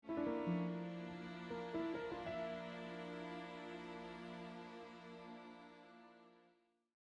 This one is quite subtle.